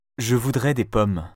les sons [ o ] bureau, tôt [ ɔ ] bonne, prof